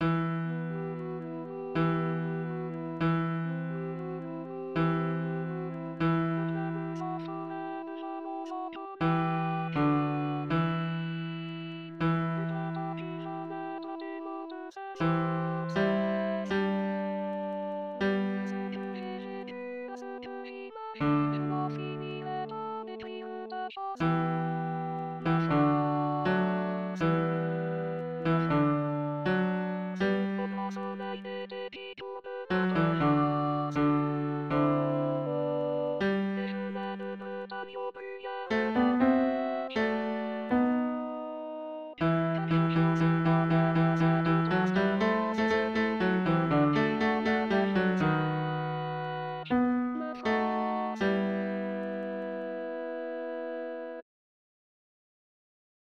Basse (.mp3)